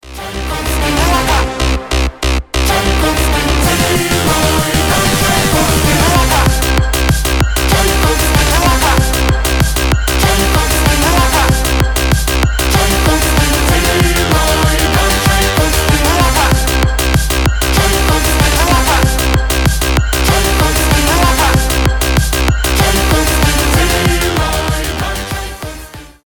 хардбасс
танцевальные , рейв
hardstyle